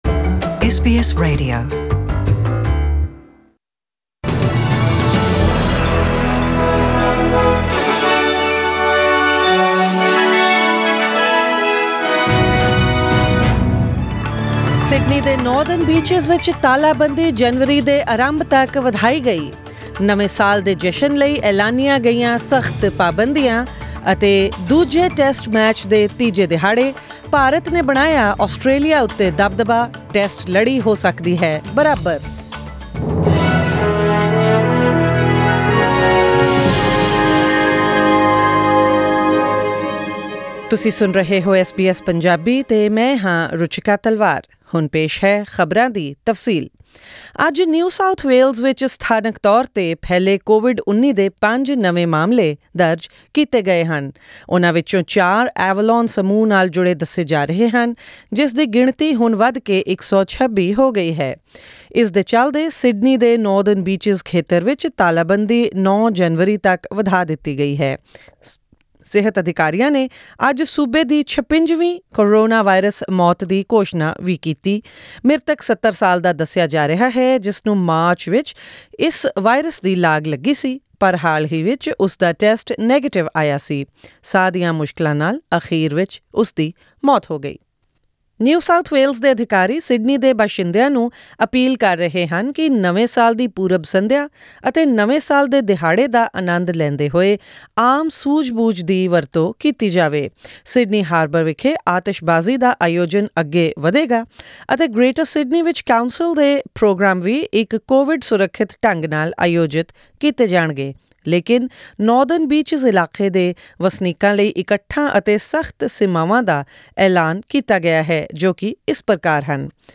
Click on the audio icon in the photo above to listen to the bulletin in Punjabi.